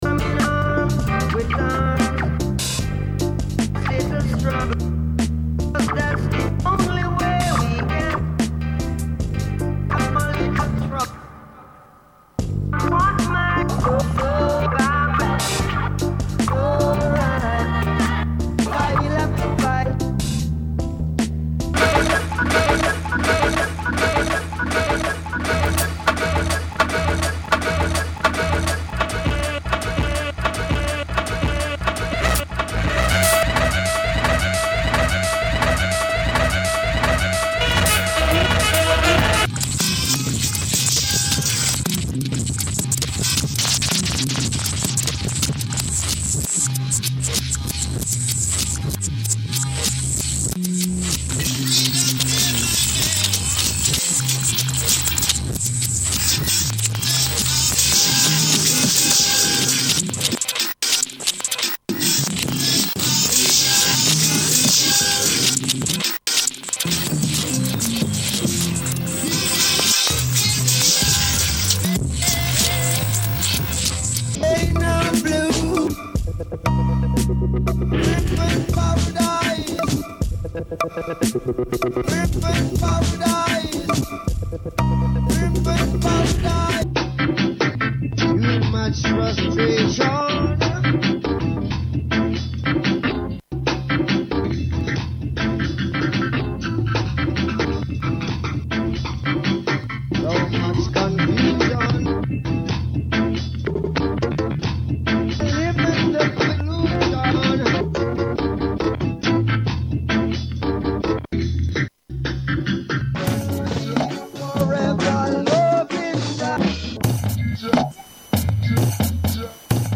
BreakBeat Roots Rock Dub Remix